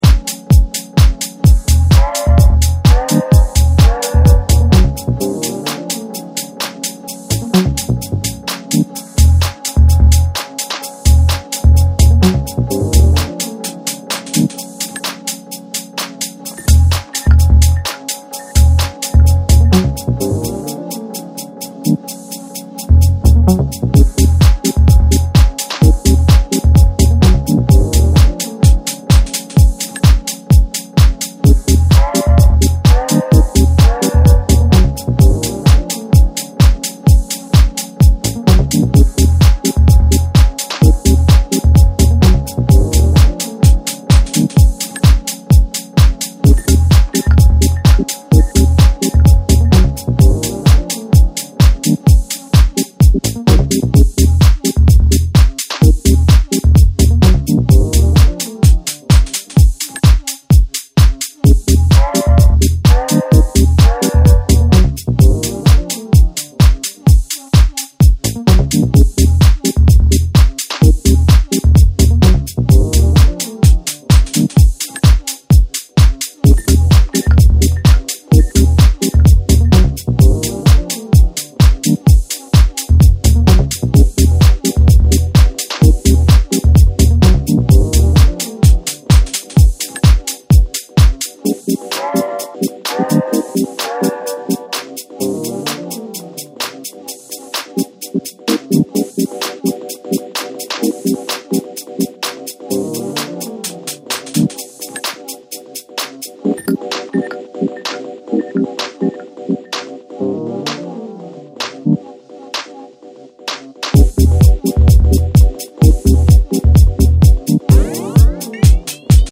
a trio of electronic beats